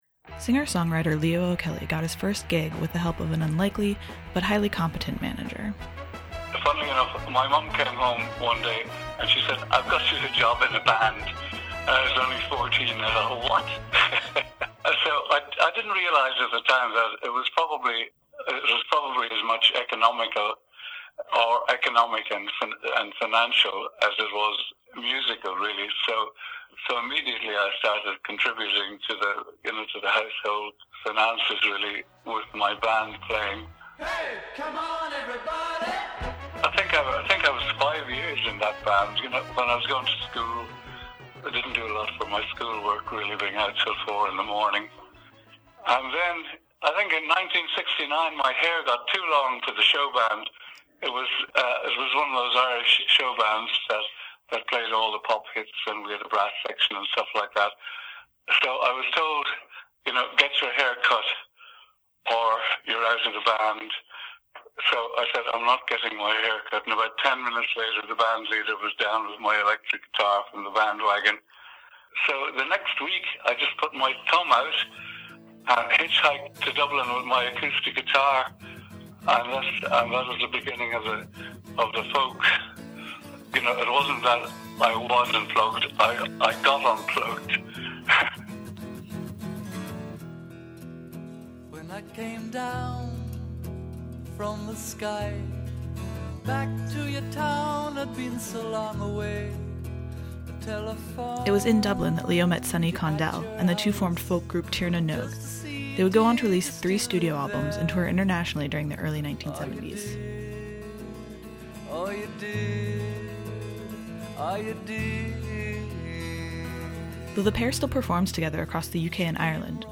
musicians